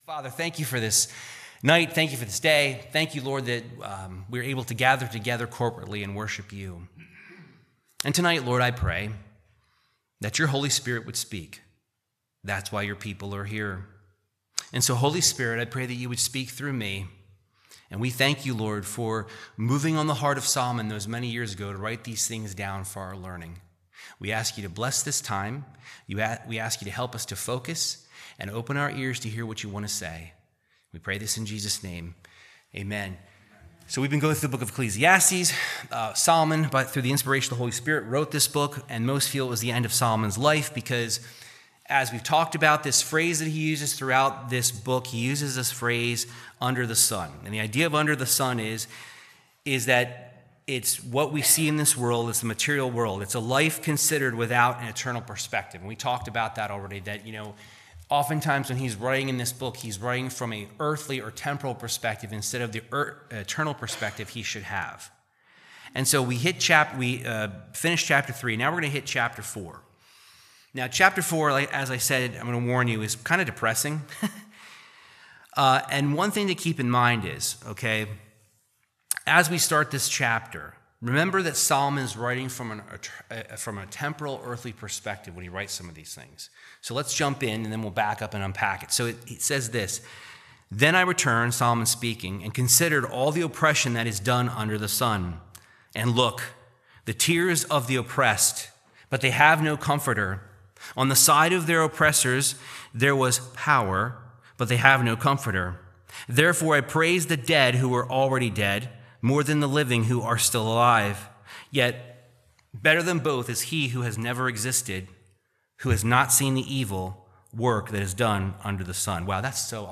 Verse by verse Bible Teaching of Ecclesiastes 4